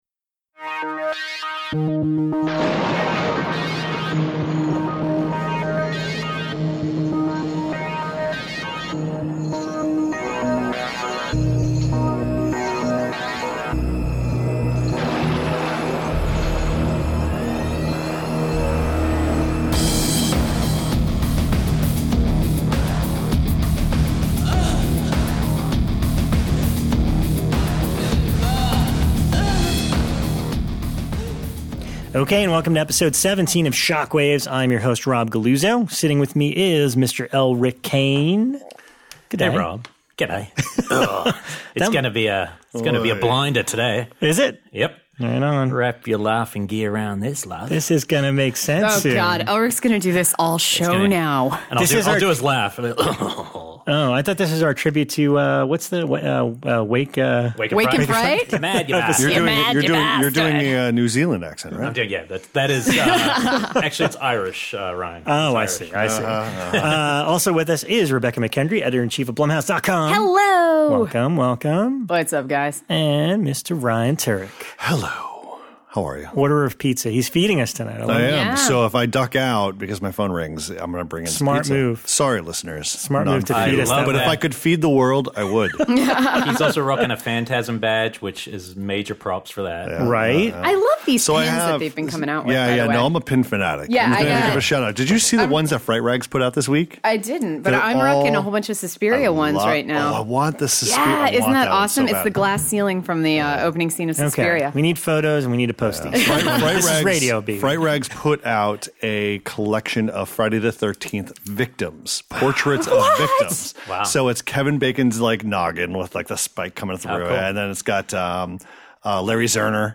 Then the group welcome on very special guest Greg McLean! They delve into the true story that inspired WOLF CREEK, his plans for the upcoming series and WOLF CREEK 3, his humble beginnings in filmmaking, teaming with James Gunn for THE BELKO EXPERIMENT and much, much more!